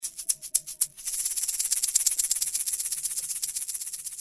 115 Bpm - Egg Shaker (5 variations)
Simple egg shaker loops, playing at 115 bpm in 5 variations.
The shaker has no sound compression.